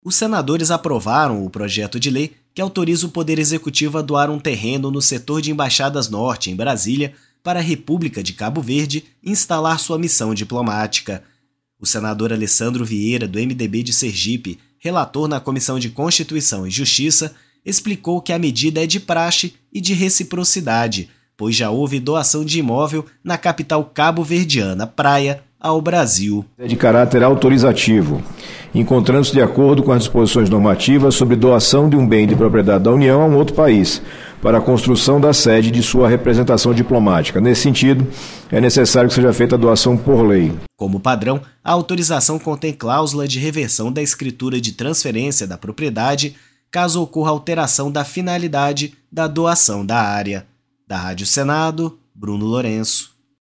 O relator, Alessandro Vieira (MDB-SE), explicou que é medida de praxe e de reciprocidade, pois já houve doação de imóvel na capital cabo-verdiana, Praia, ao Brasil.